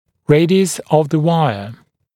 [‘reɪdɪəs əv ðə ‘waɪə][‘рэйдиэс ов зэ ‘уайэ]радиус проволоки